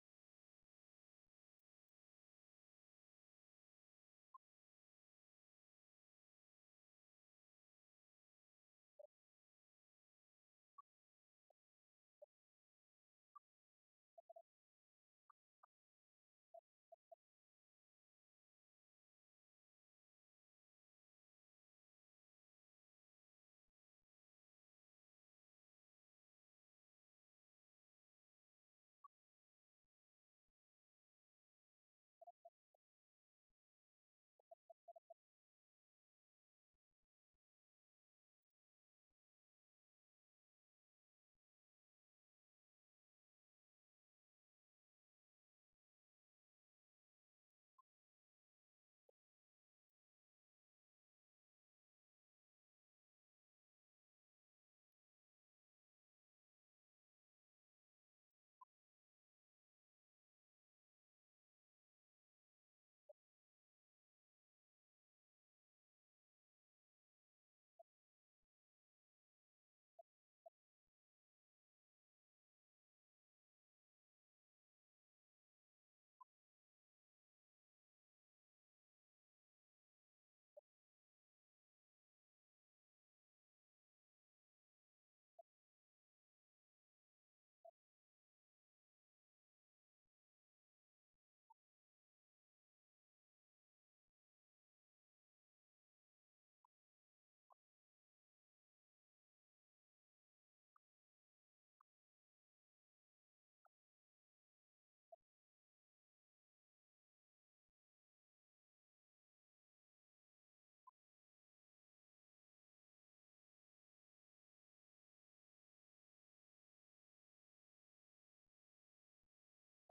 Liberté par la chorale de l'église Notre-Dame
enregistrement de 1948
Genre strophique
archives sonores réenregistrées